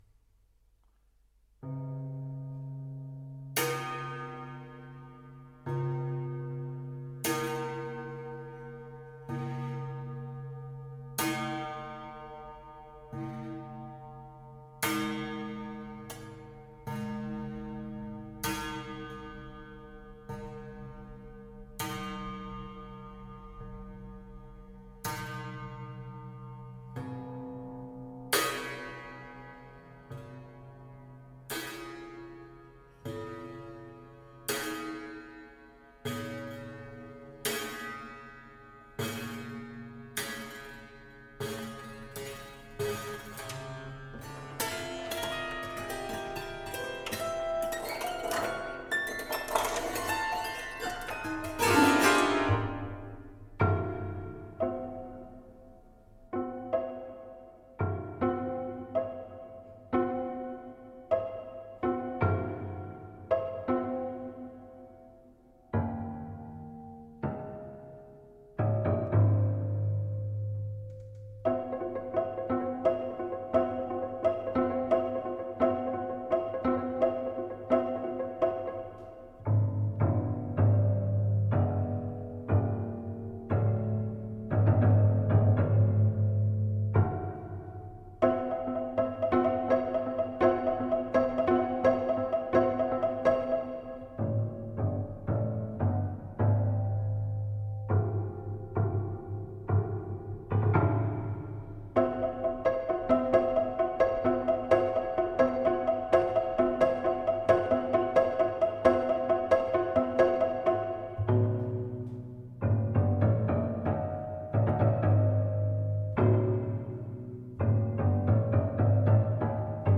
composition et piano